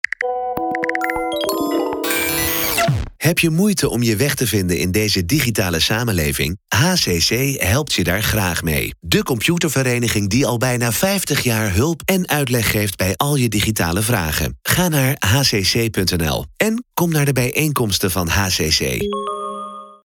Deze week zijn er al diverse radio spotjes over de HCC op de regionale zenders en op Radio 5 te horen geweest.
HCC_Hoofdspot.mp3